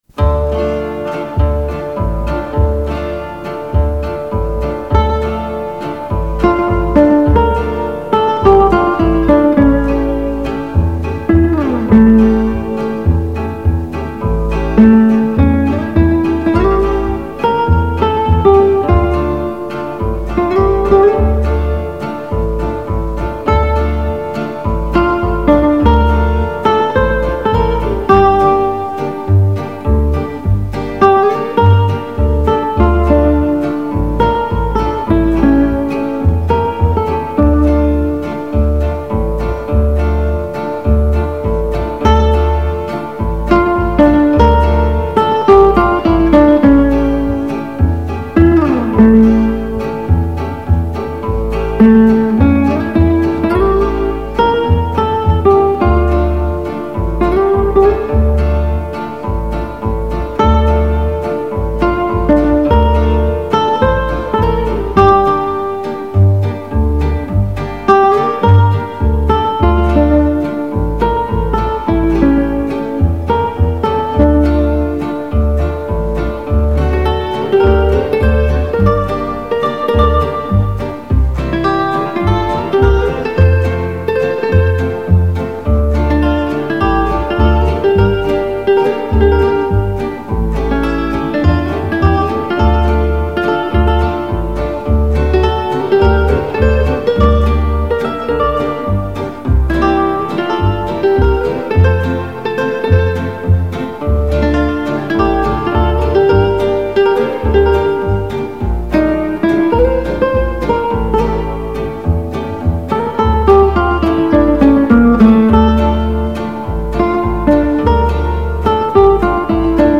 연주곡 기타